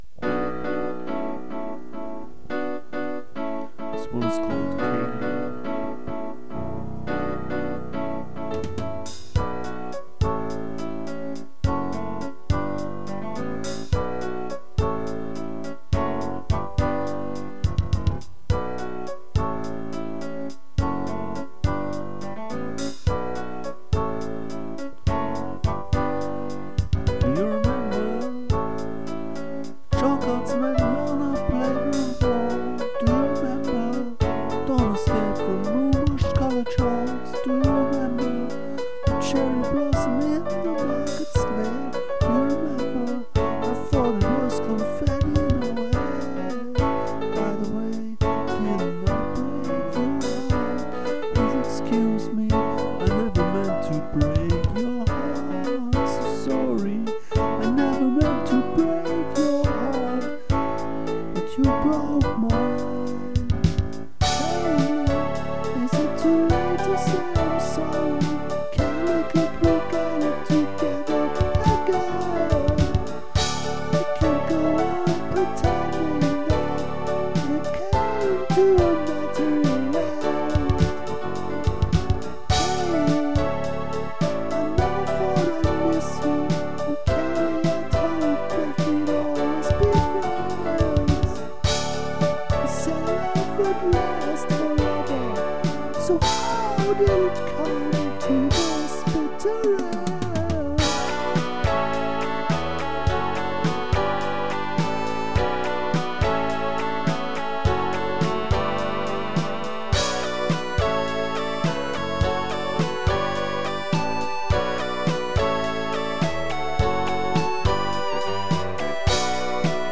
8 of these songs were recorded with a karaoke program